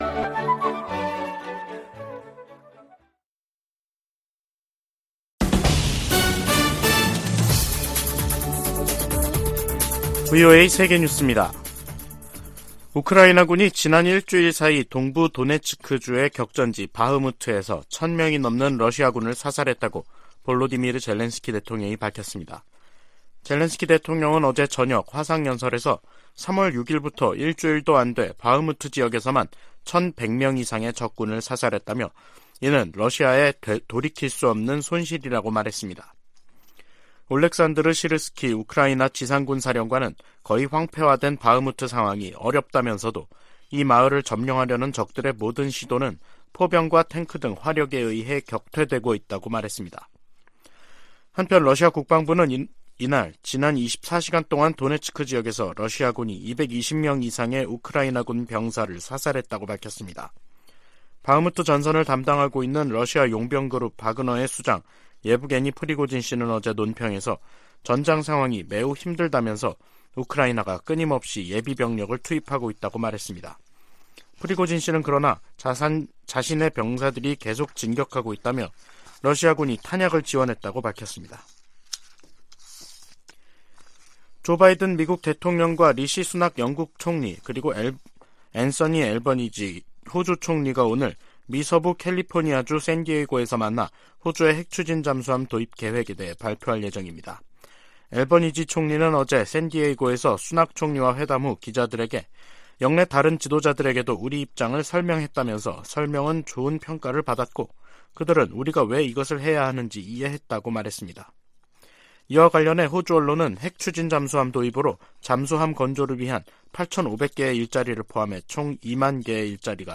VOA 한국어 간판 뉴스 프로그램 '뉴스 투데이', 2023년 3월 13일 2부 방송입니다. 북한이 12일 전략순항미사일 수중발사훈련을 실시했다고 다음날 대외 관영 매체들이 보도했습니다. 미국과 한국은 ‘자유의 방패’ 연합연습을 시작했습니다. 미 국무부가 북한의 최근 단거리 탄도미사일 발사를 규탄하며 대화 복귀를 촉구했습니다.